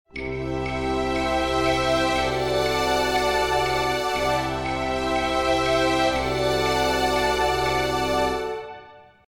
Enchainement simple de deux accords pour apporter un effet optimiste (à la Disney) à vos compositions.
Cet enchainement de deux accords qui permet d’amener une touche de merveilleux ou d’aventure est tout simple à mettre en pratique. Il suffit de jouer un accord majeur (ex : Do majeur) puis d’enchainer sur l’accord majeur situé un ton au dessus (Ré majeur), tout en maintenant la note fondamentale du premier accord (note do grave) :